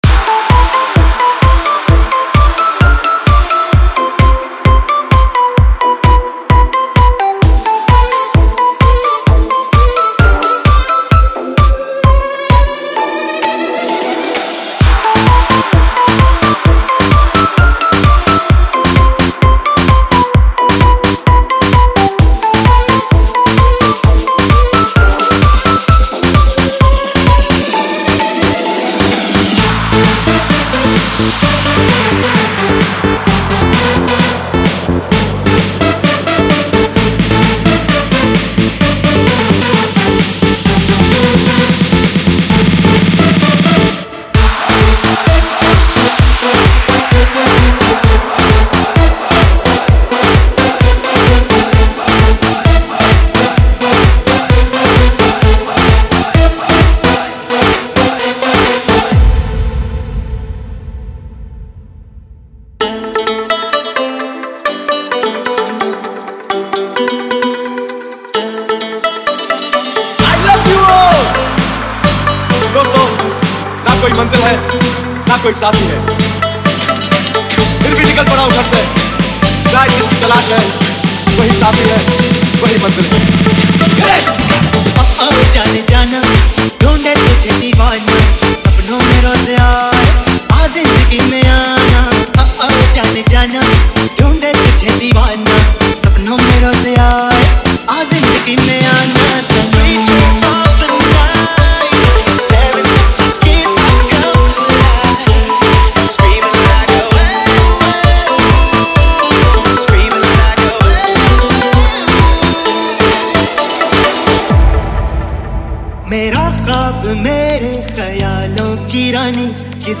Singer : Old Hindi DJ Remix